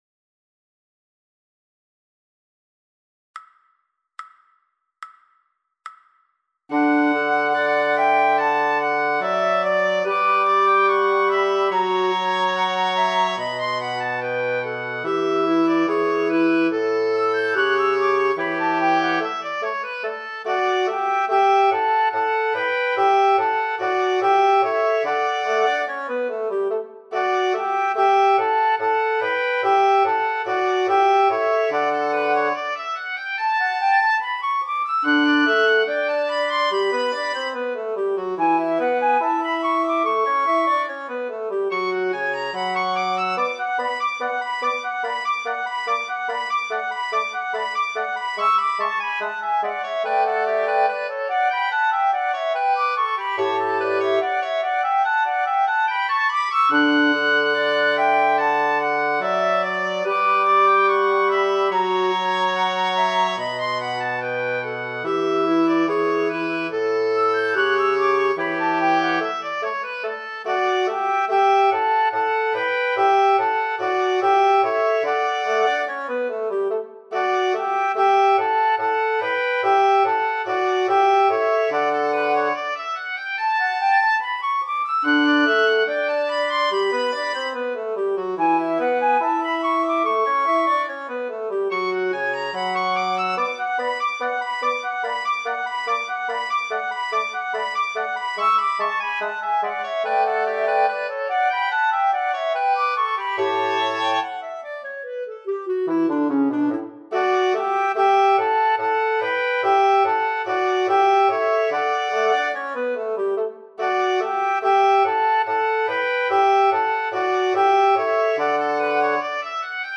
All Creatures - slow.mp3